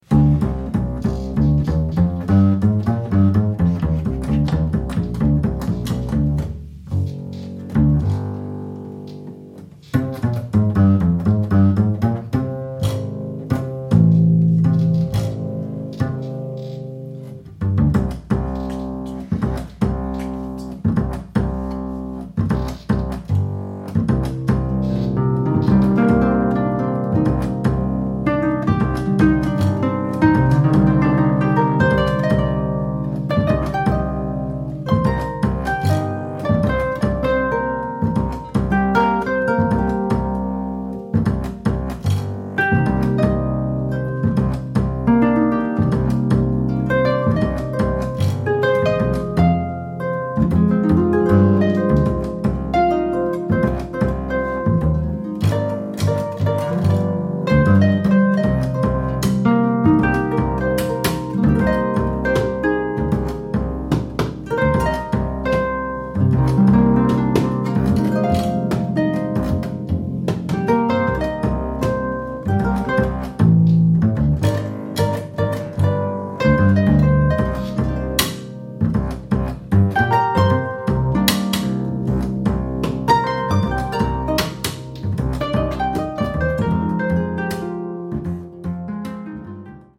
jazz
bassist
harpist
Home recorded album